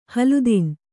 ♪ haludin